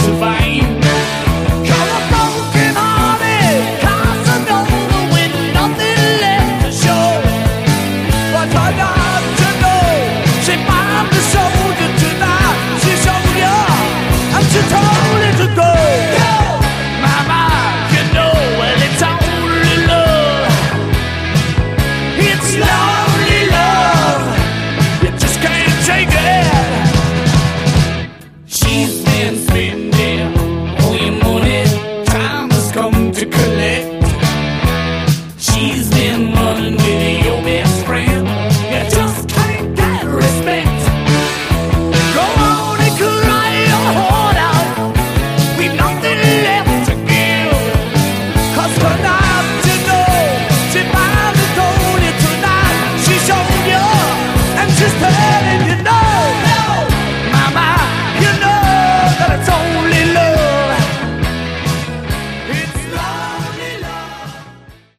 Category: Hard Rock
guitars, keyboards
drums, percussion